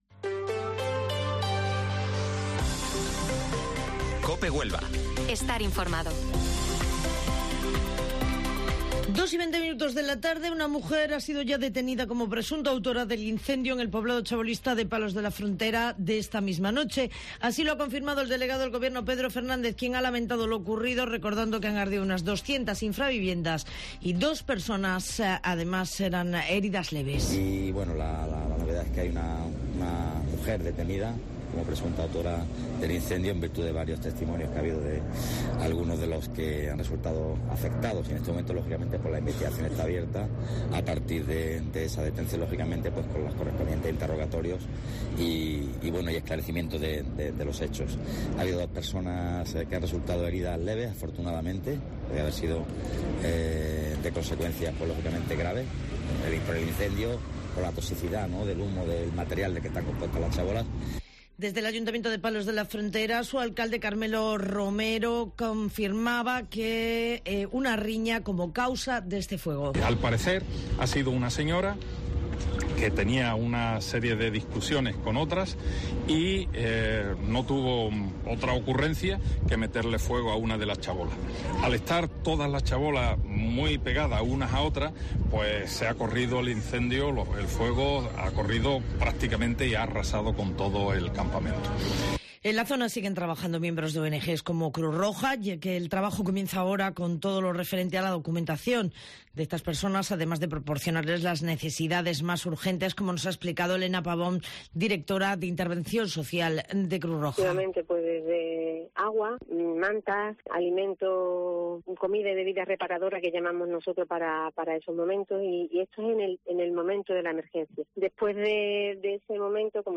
Informativo Mediodía COPE Huelva 25 de septiembre